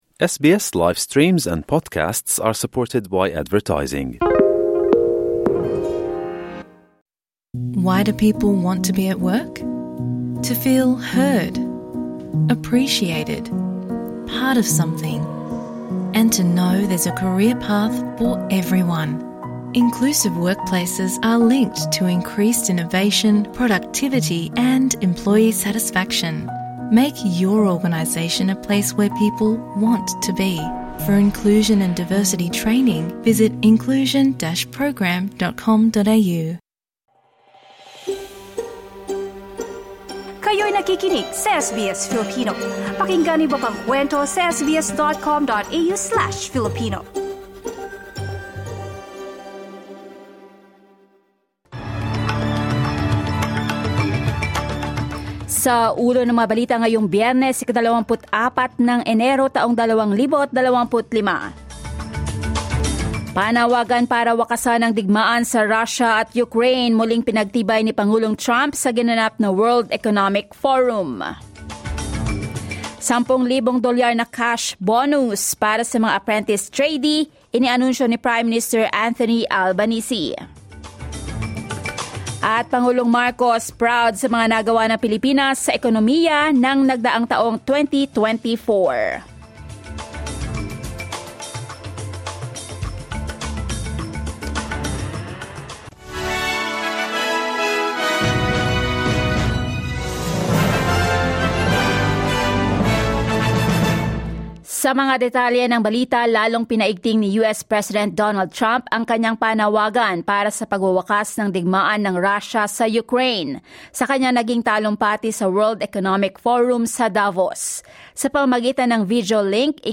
SBS News in Filipino, Friday 24 January 2025